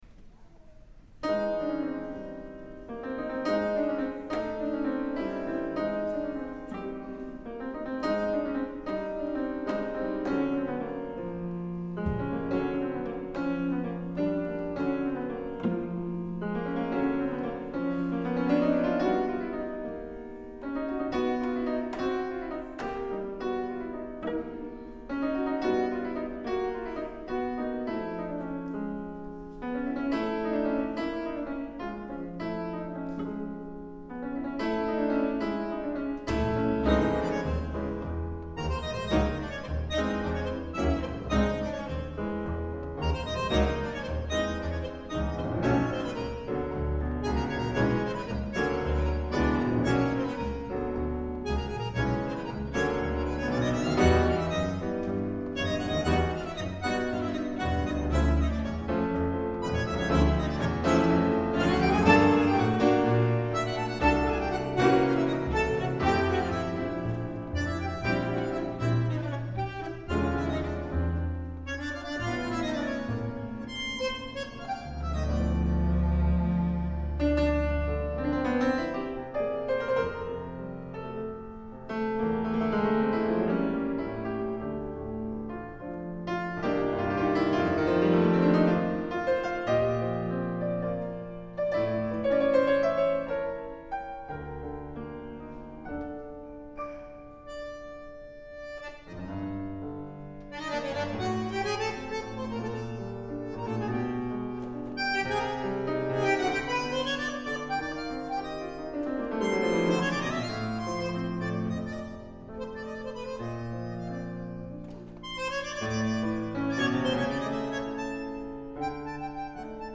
with quartet